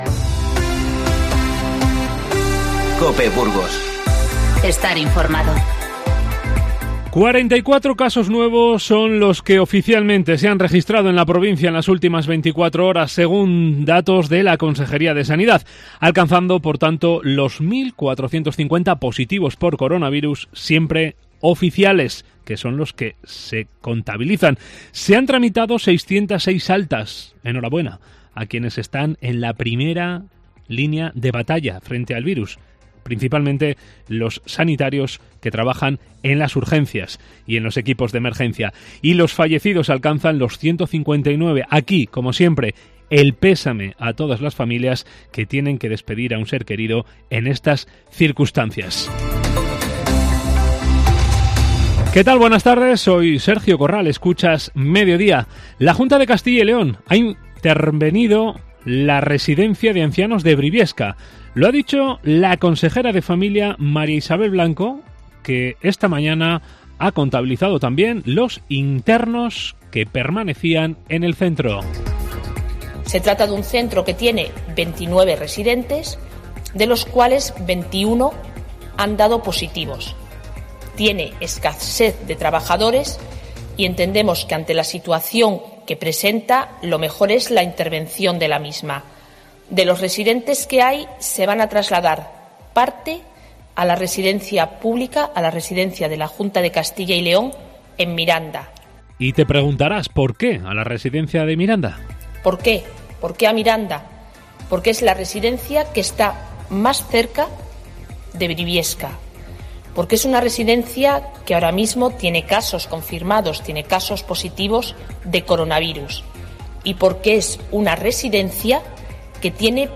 INFORMATIVO MEDIODÍA 17/4